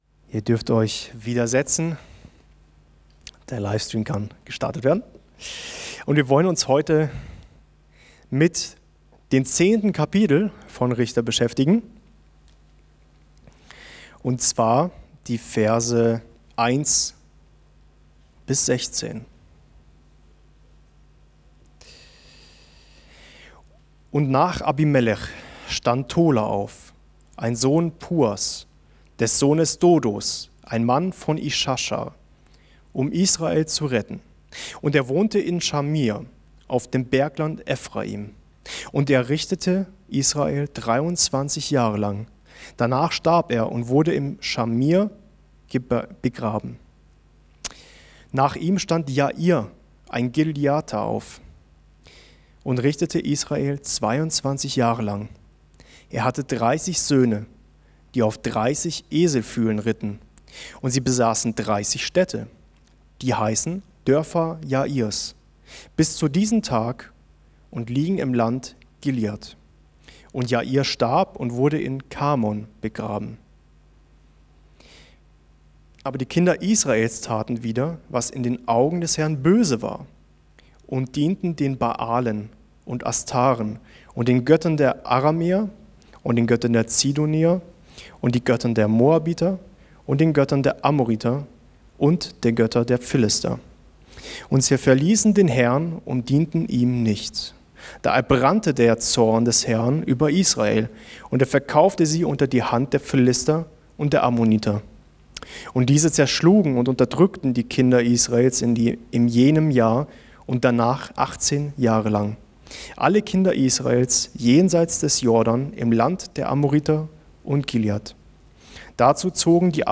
Wie Geht es Deinem Herzen? ~ Mittwochsgottesdienst Podcast